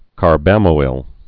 (kär-bămō-ĭl)